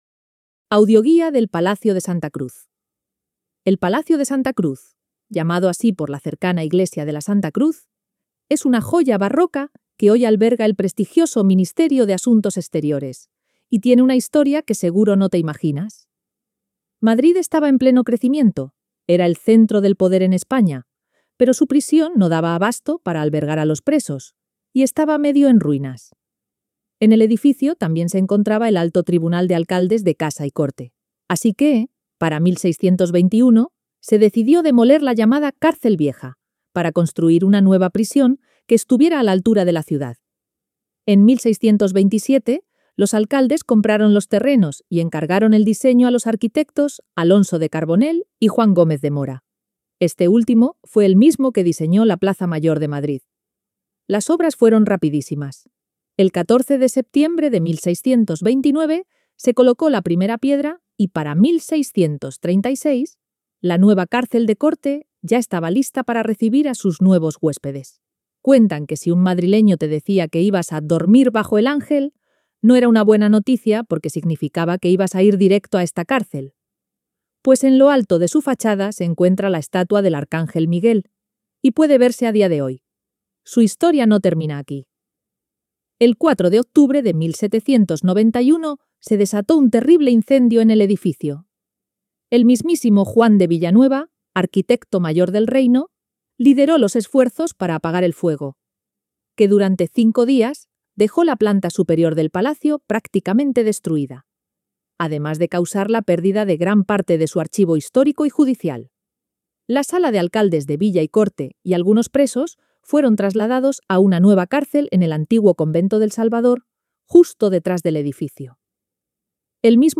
Audioguía del Palacio de Santa Cruz